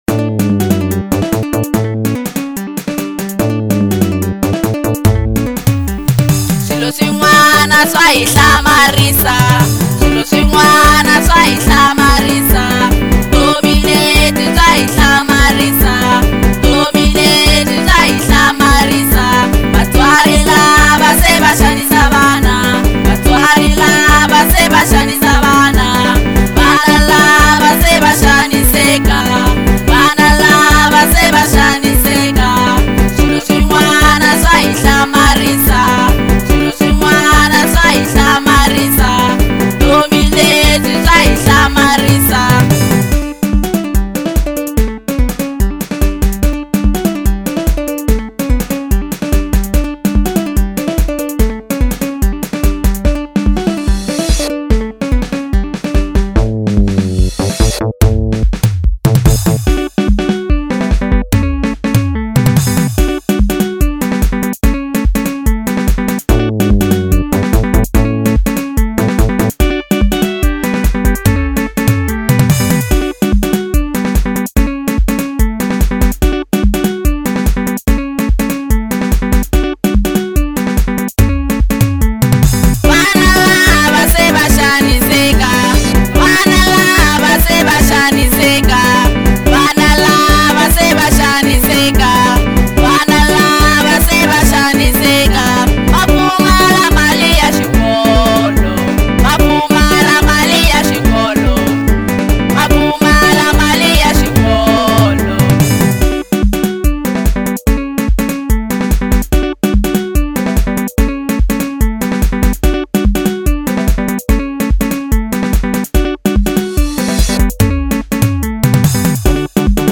04:37 Genre : African Kwasa Size